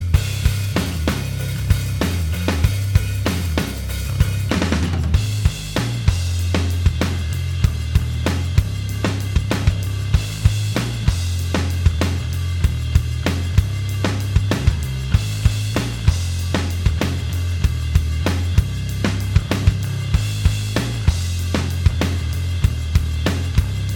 Minus All Guitars Rock 3:35 Buy £1.50